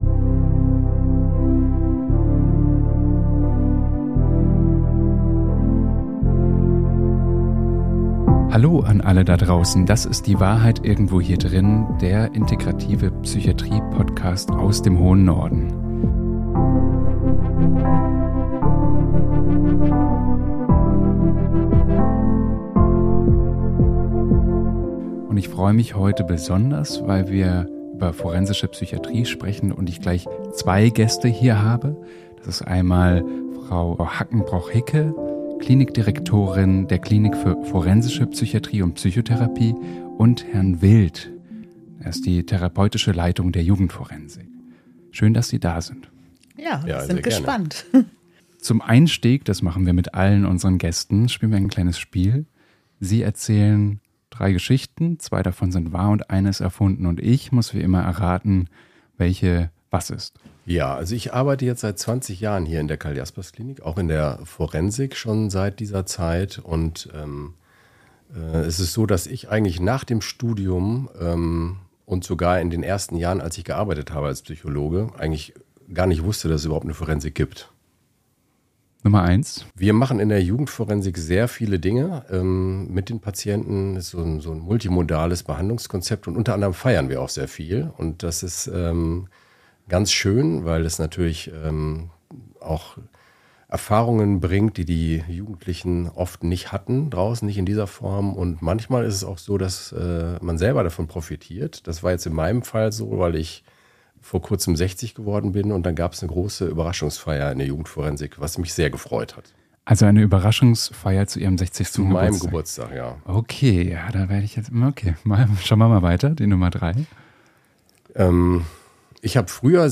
#14 FORENSISCHE PSYCHIATRIE Experten-Talk ~ Die Wahrheit Irgendwo Hier Drinnen Podcast